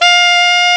Index of /90_sSampleCDs/Roland L-CDX-03 Disk 1/SAX_Alto Short/SAX_A.mf 414 Sh
SAX A.MF F0L.wav